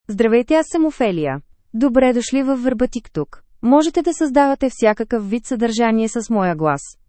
Ophelia — Female Bulgarian (Bulgaria) AI Voice | TTS, Voice Cloning & Video | Verbatik AI
OpheliaFemale Bulgarian AI voice
Ophelia is a female AI voice for Bulgarian (Bulgaria).
Voice sample
Listen to Ophelia's female Bulgarian voice.
Female